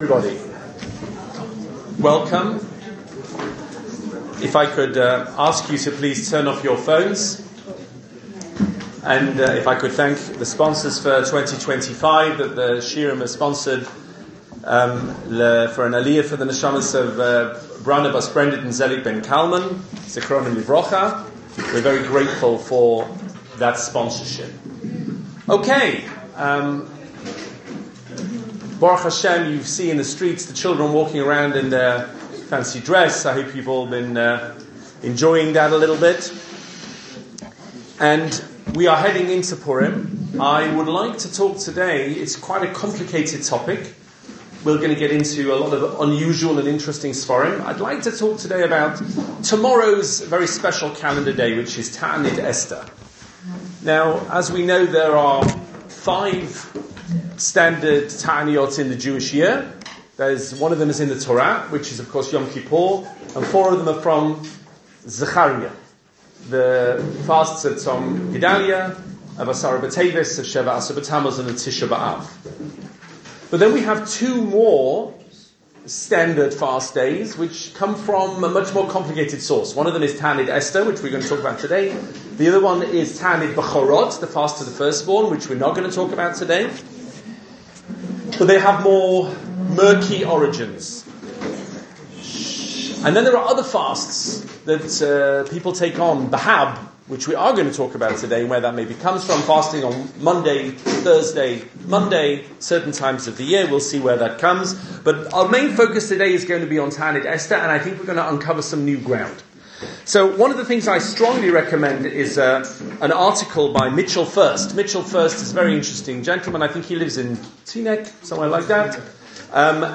The Origins of Ta’anit Esther 2025 shiur